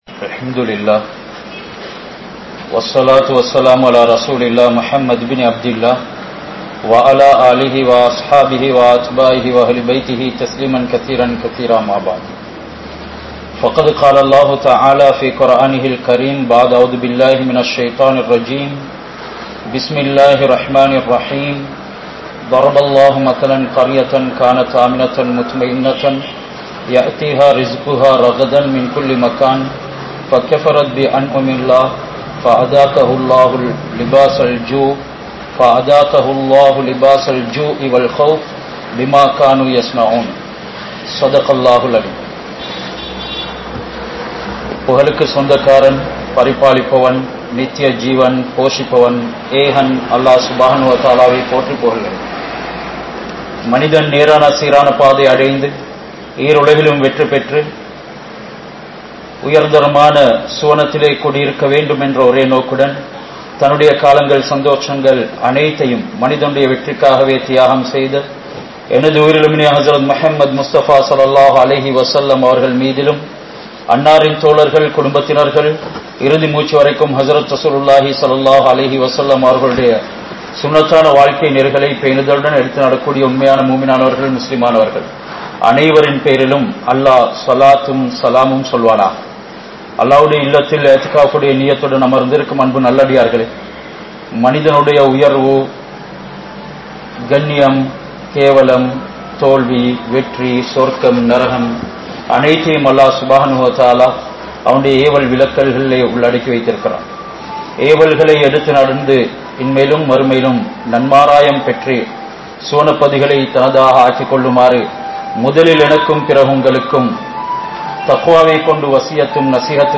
Niumathkalai Parikkum Paavangal (நிஃமத்களை பறிக்கும் பாவங்கள்) | Audio Bayans | All Ceylon Muslim Youth Community | Addalaichenai
Saliheen Jumua Masjidh